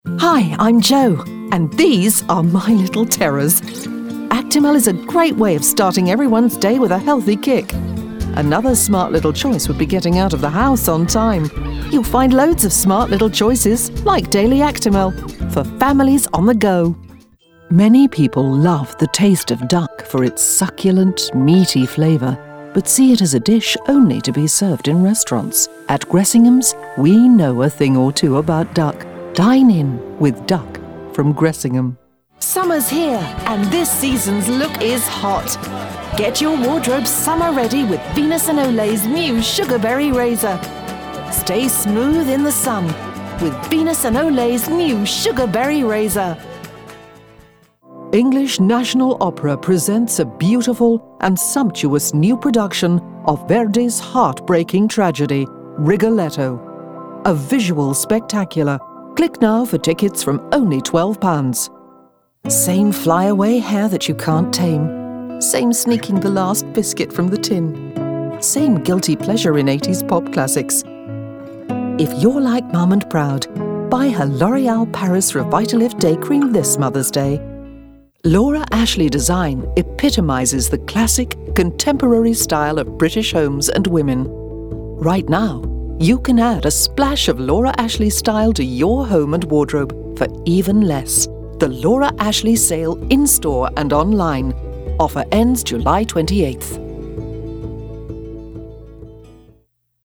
RP English Neutral, Mature voice, Warm, Authoritative, Trustworthy, Versatile